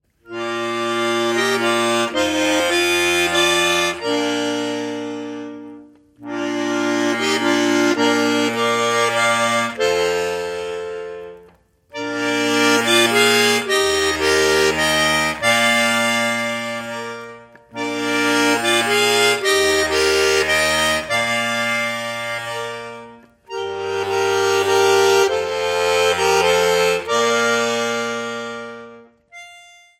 77 der bekanntesten Lieder aus allen Landesteilen
Besetzung: Schwyzerörgeli mit CD